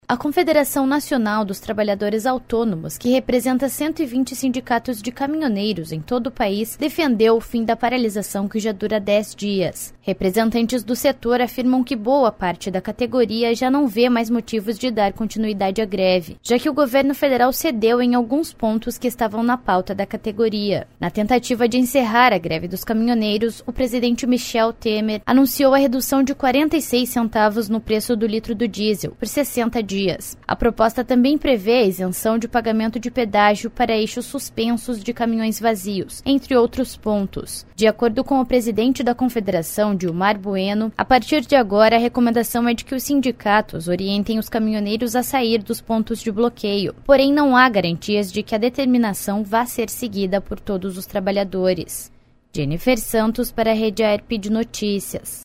30.05 – BOLETIM SEM TRILHA – Sindicatos pedem que caminhoneiros encerrem a greve